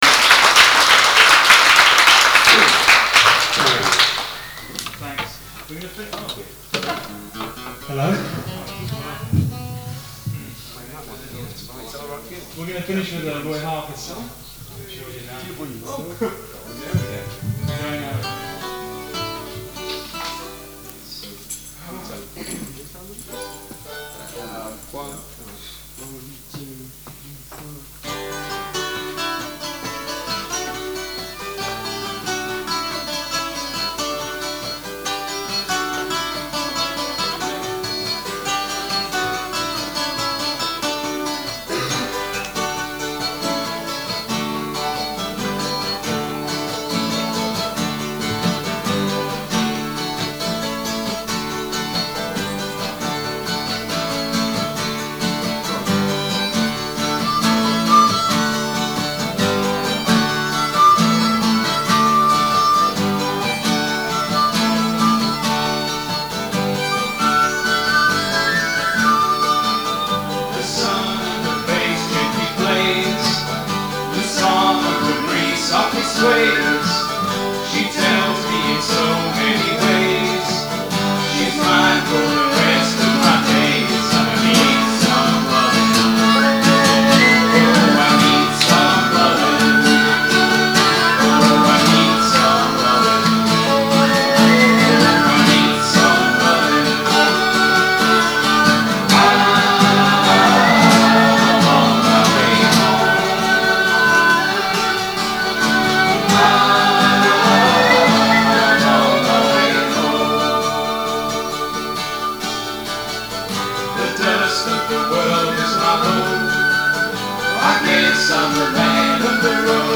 Guitars, Bass, Percussion, Vocals